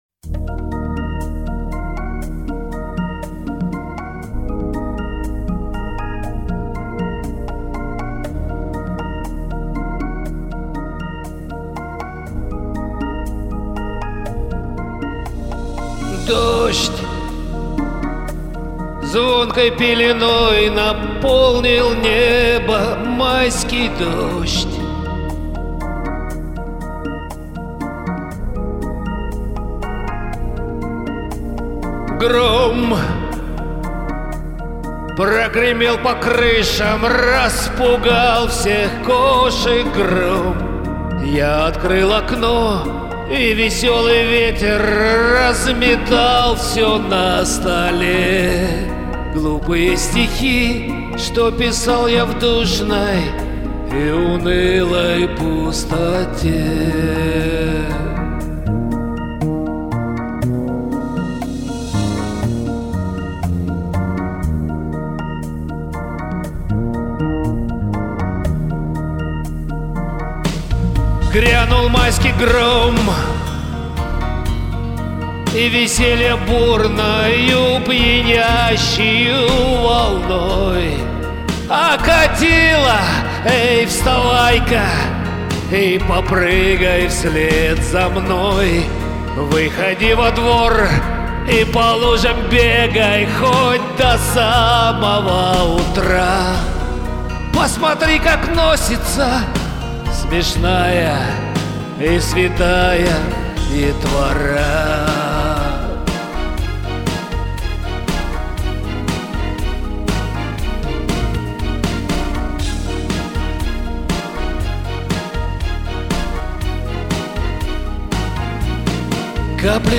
Кода очень яркая,ты молодец,до мурашек проняло.
Эффекты классно вписались!